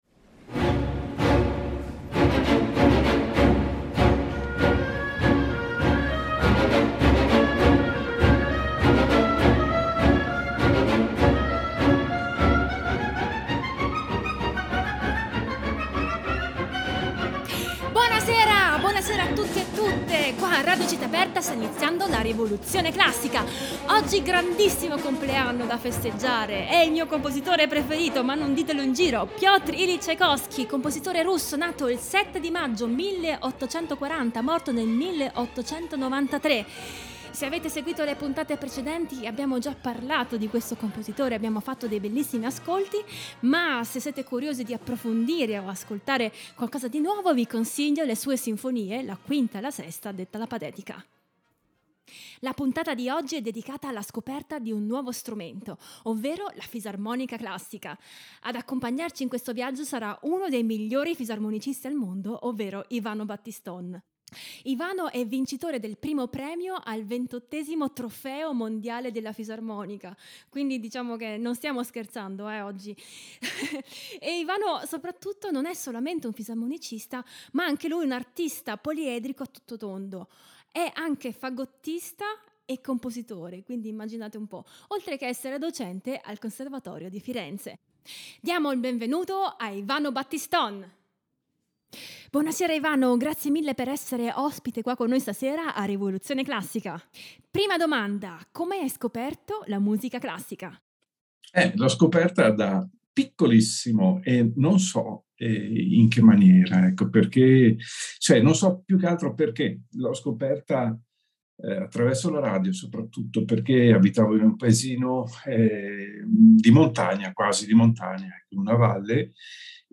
fisarmonica
flauto dolce
Ospite di questa puntata il fisarmonicista